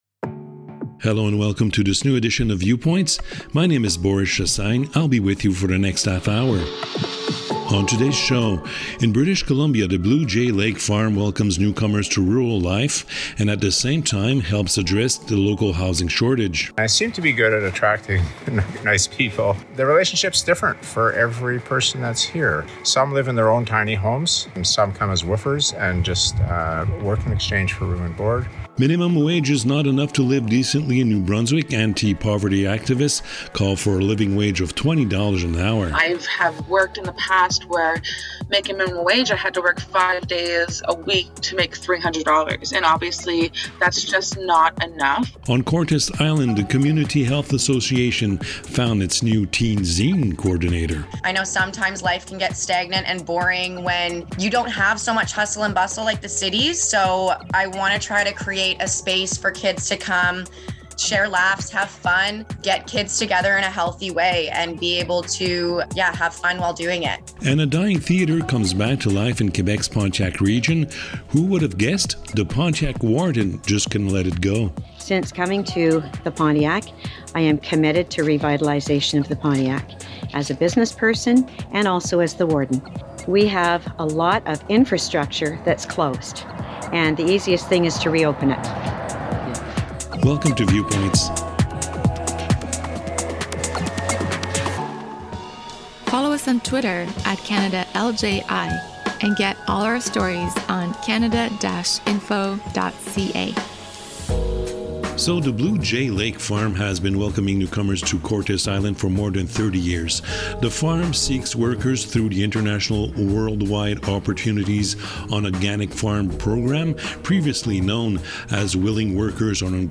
The Community Radio Fund of Canada produces Viewpoints, a 30 minute weekly news magazine aired on some 30 radio stations across Canada.